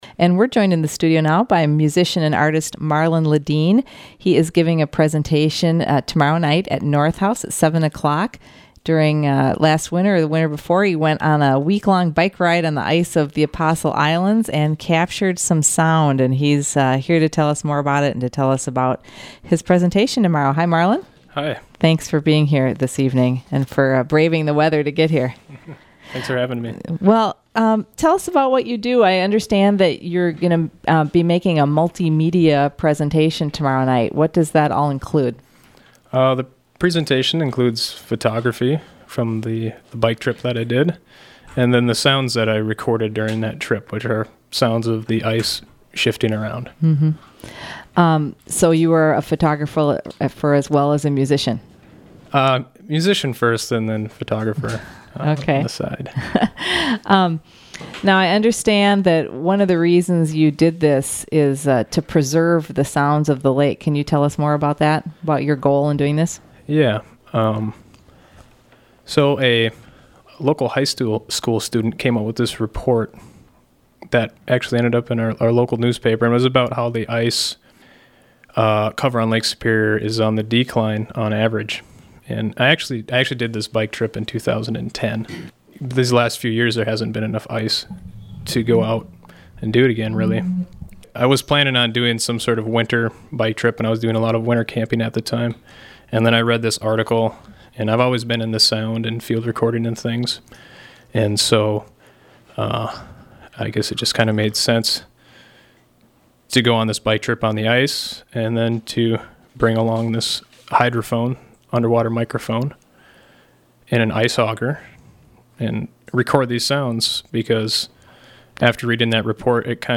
The interview includes several segments of those recordings.